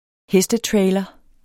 Udtale [ ˈhεsdə- ]